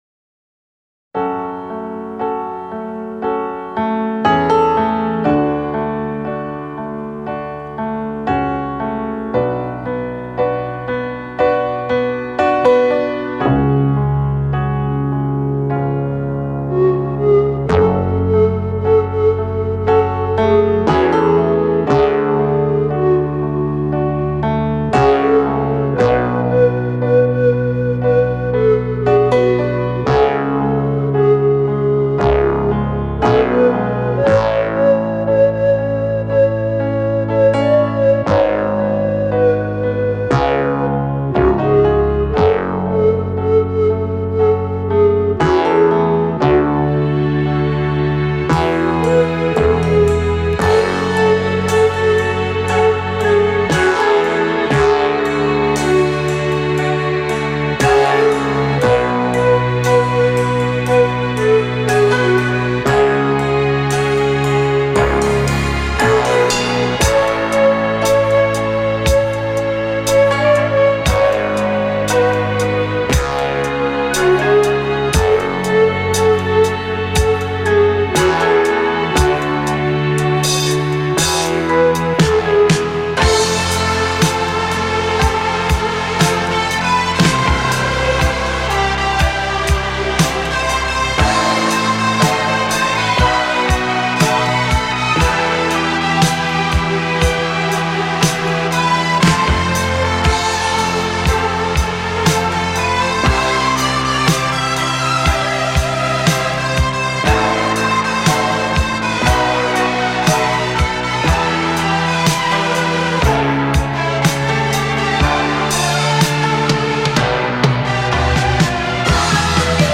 Жанр: Electronic; Битрэйт